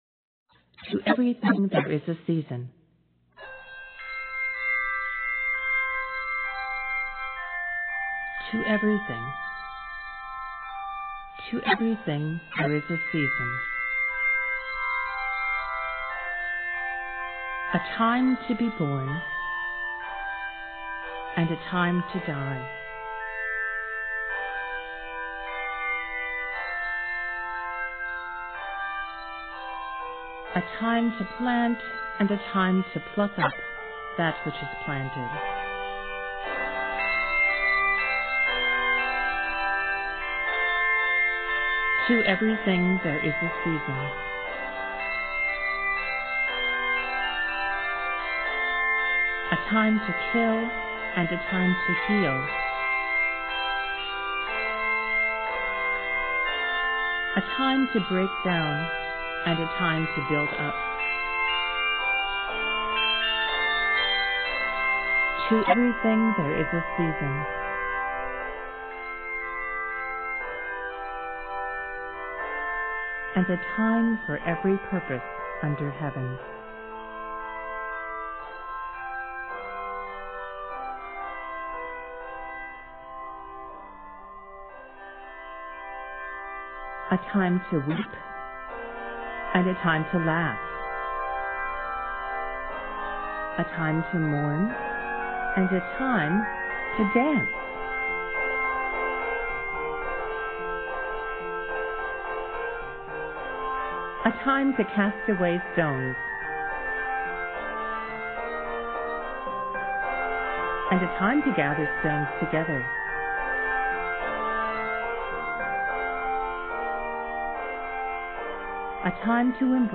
complete with optional narration